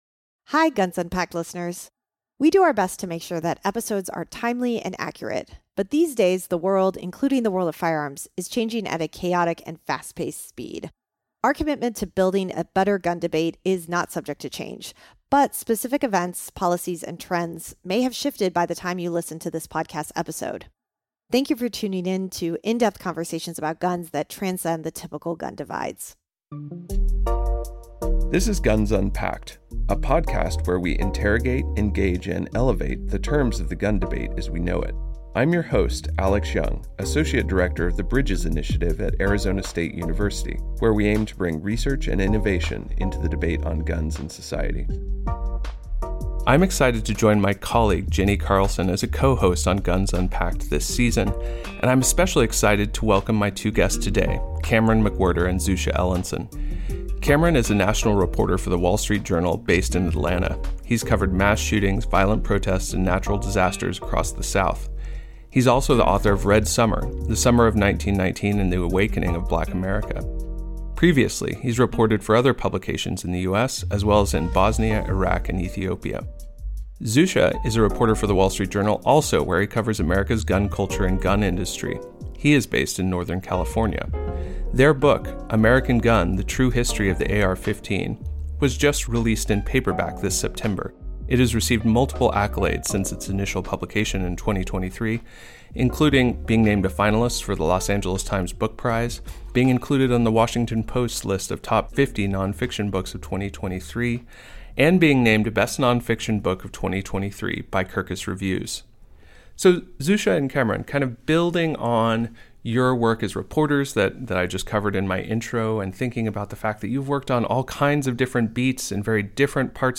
Today’s conversation explores the AR-15’s technological development, its transformation from a failed weapon of war into a cultural and political icon, and how both rights advocates and regulation activists have contributed to the mythology surrounding it. The guests also examine media coverage of mass shootings, the impact of the 1994 Assault Weapons Ban, and the future of regulation in a nation with more than 20 million AR-15s in circulation.